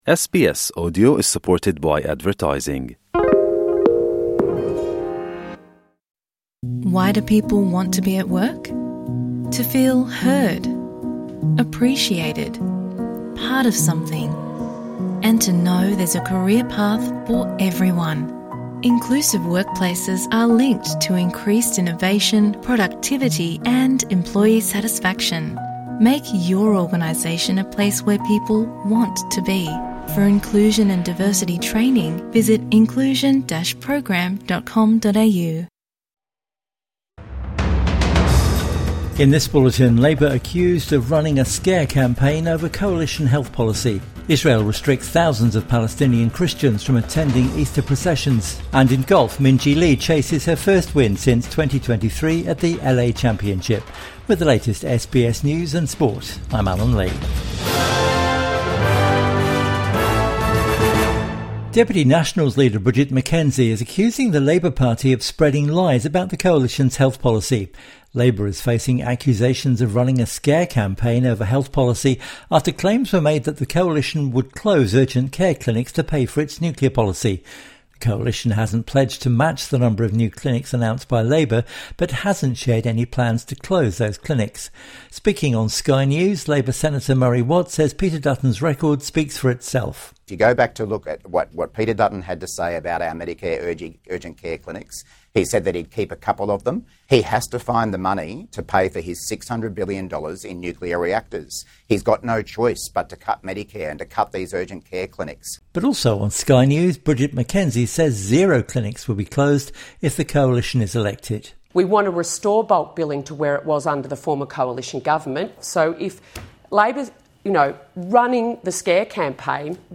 Labor accused of new 'mediscare' campaign | Evening News Bulletin 20 April 2025